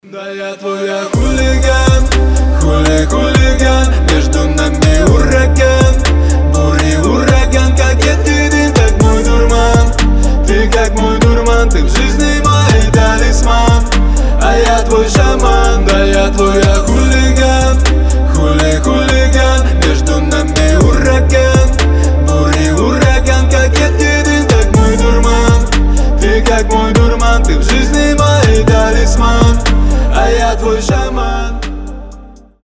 мужской голос